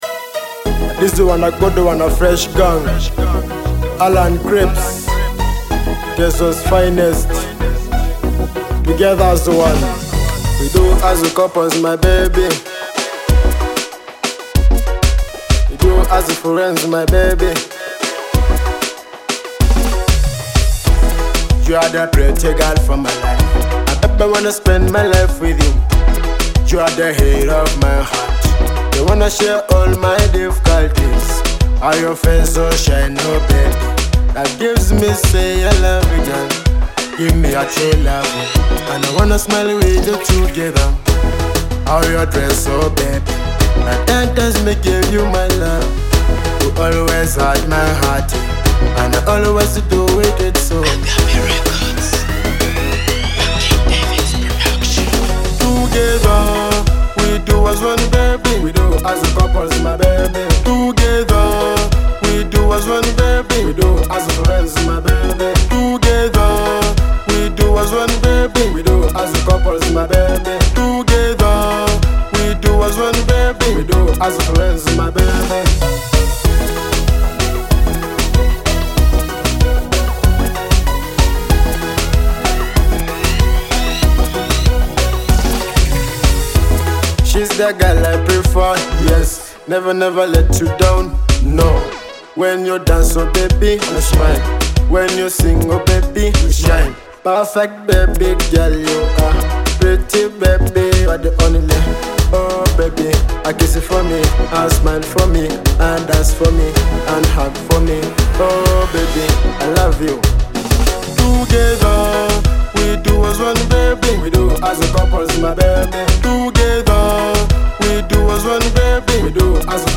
a leading Teso music artist
Experience the authentic African rhythms